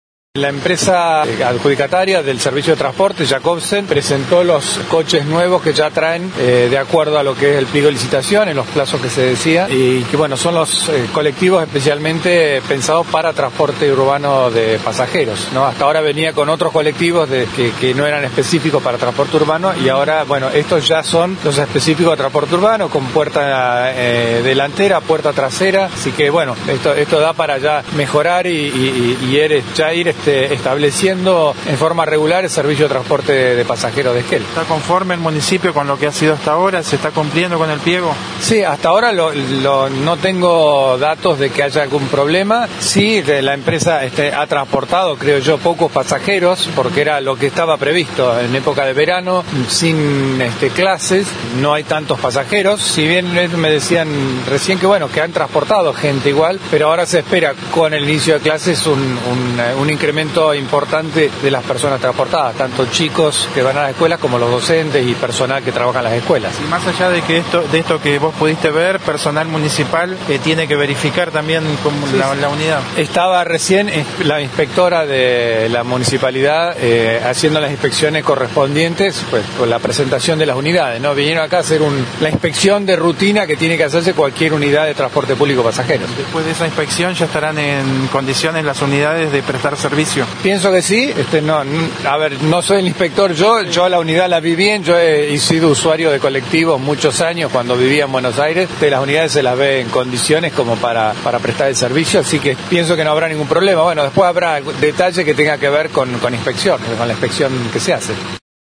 Hasta el momento la empresa prestaba el servicio con colectivos que no eran específicos para el transporte urbano, en cambio las nuevas unidades cumplen con las condiciones del pliego de la concesión, siendo colectivos preparados con puerta delantera y trasera. Escuché la palabra del Intendente luego de revisar estas unidades.